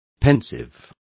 Also find out how meditabundo is pronounced correctly.